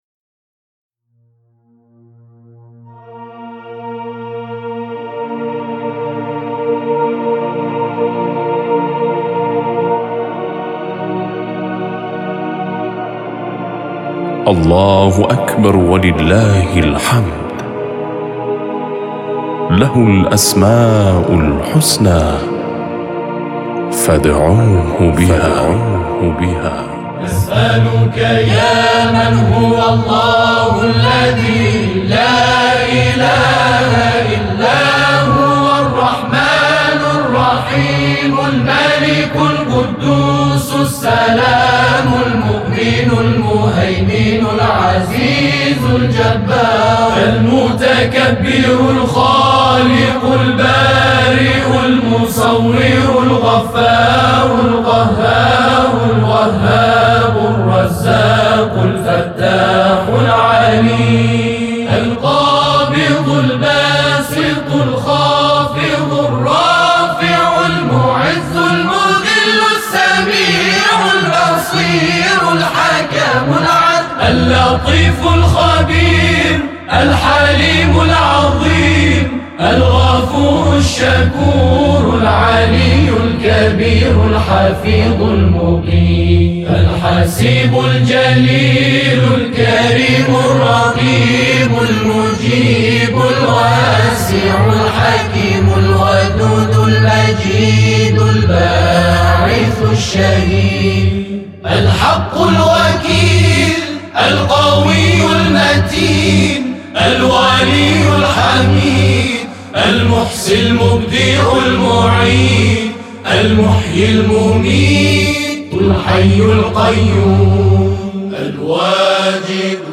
گروه تواشیح ولیعصر